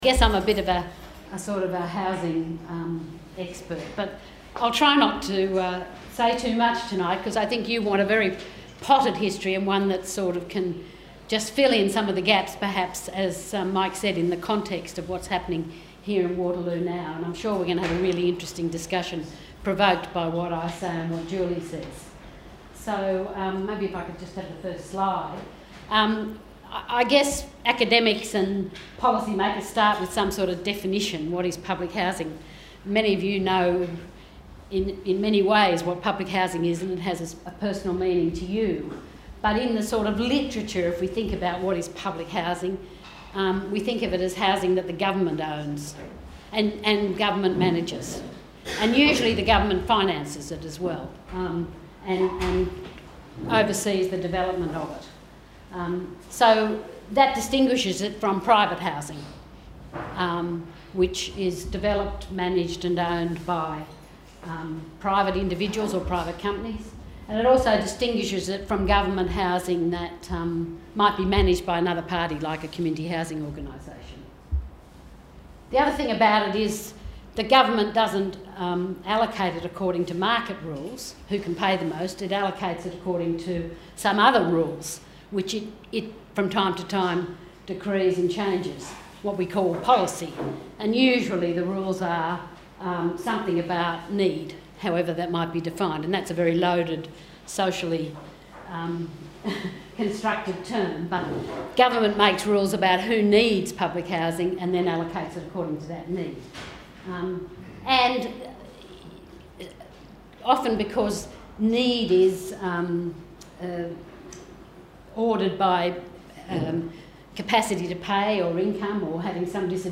The REDWatch forum on Public Housing held on 6 July 2017 was recorded and this is an edited recording from that meeting.